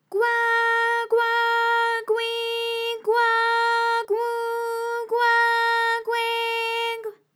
ALYS-DB-001-JPN - First Japanese UTAU vocal library of ALYS.
gwa_gwa_gwi_gwa_gwu_gwa_gwe_gw.wav